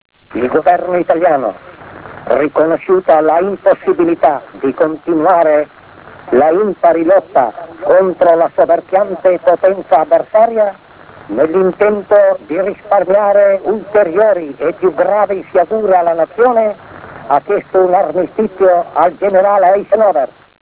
Badoglio proclama per radio l’armistizio 1943 formato WAV
Badoglioproclamaperradiol'armistizio1943.wav